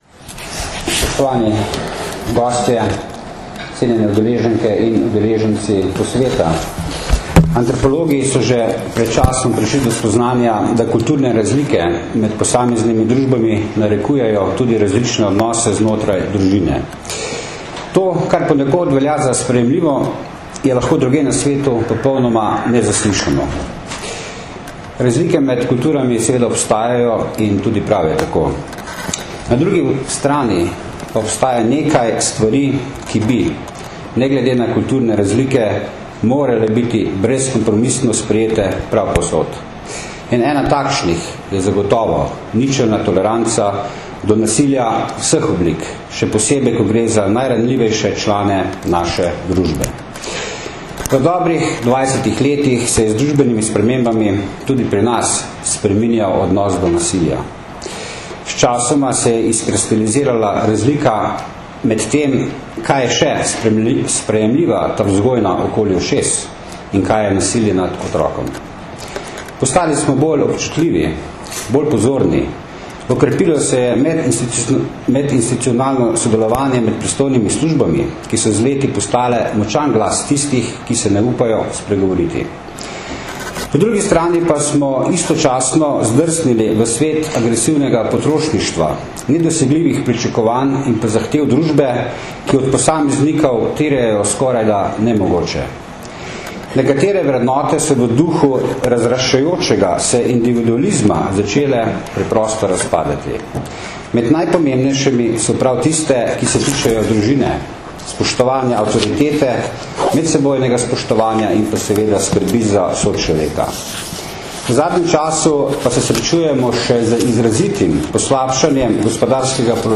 Na Brdu pri Kranju se je danes, 4. aprila 2013, začel dvodnevni strokovni posvet z naslovom Otrok v vlogi žrtve in storilca, ki ga že dvanajsto leto zapored organizirata Generalna policijska uprava in Društvo državnih tožilcev Slovenije v sodelovanju s Centrom za izobraževanje v pravosodju.
Zvočni posnetek nagovora generalnega direktorja policije Stanislava Venigerja (velja govorjena beseda) (mp3)